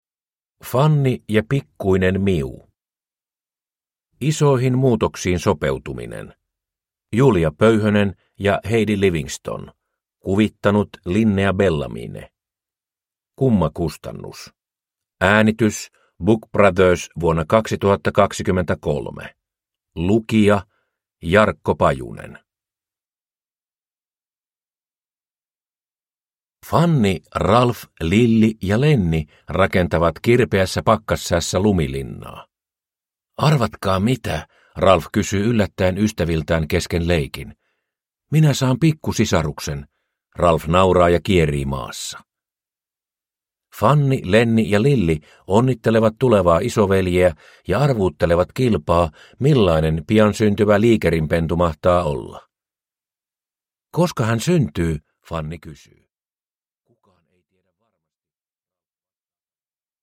Fanni ja pikkuinen Miu – Ljudbok – Laddas ner